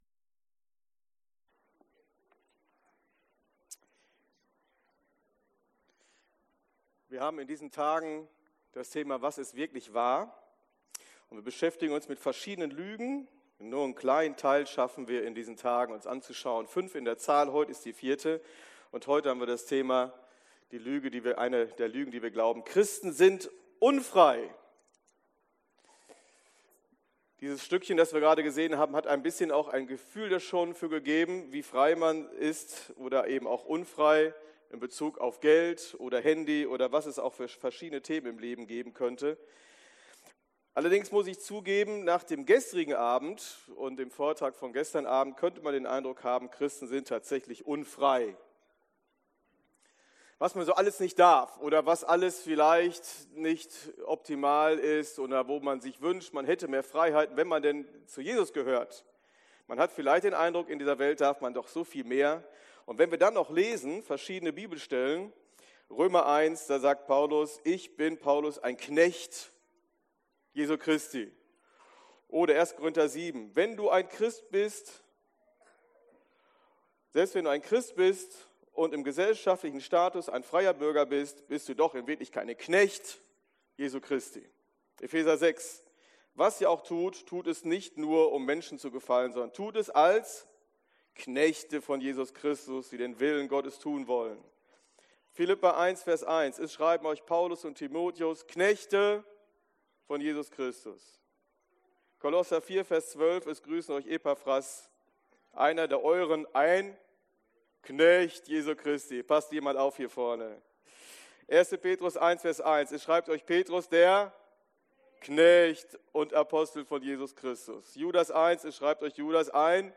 Predigtdetails anzeigen und abspielen